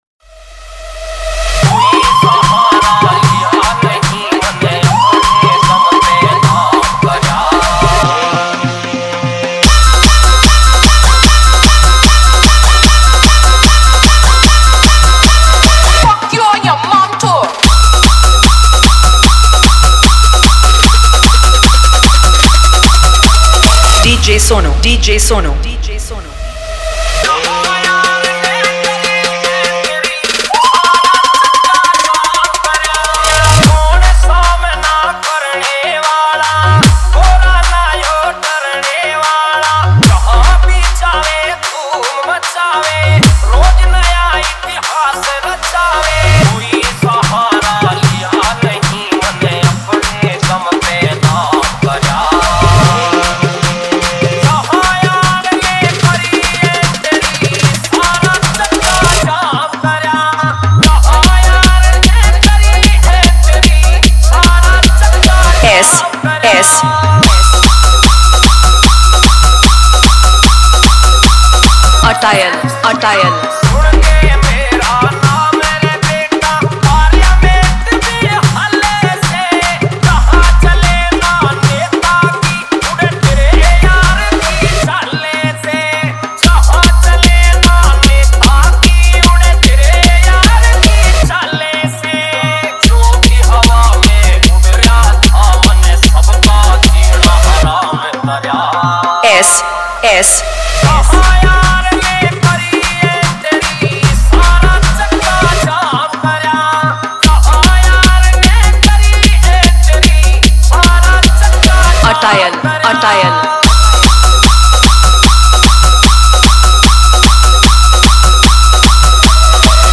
Haryanvi Remix Song